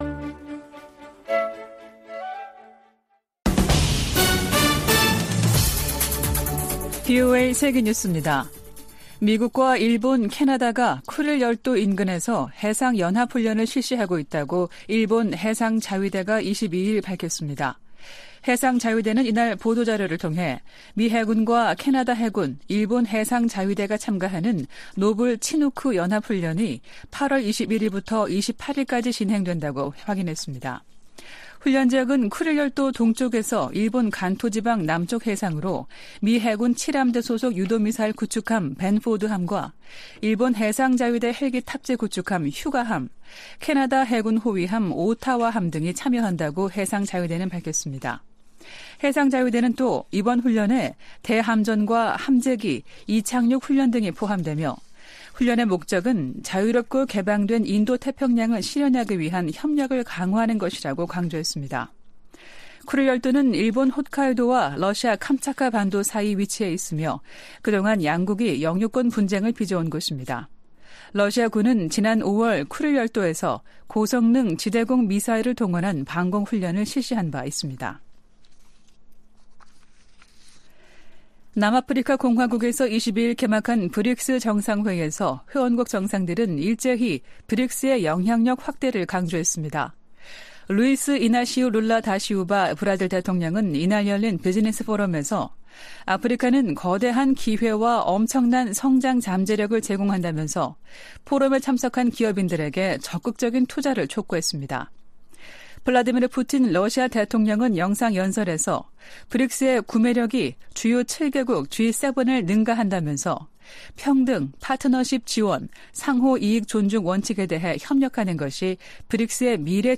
VOA 한국어 아침 뉴스 프로그램 '워싱턴 뉴스 광장' 2023년 8월 24일 방송입니다. 미 국무부는 탄도미사일 기술이 이용되는 북한의 모든 발사는 유엔 안보리 결의 위반임을 거듭 지적했습니다. 미 국방부가 북한의 위성 발사 통보와 관련해 한국·일본과 긴밀히 협력해 대응하겠다고 밝혔습니다. 미국 정부가 미국인의 북한 여행을 금지하는 조치를 또다시 연장했습니다.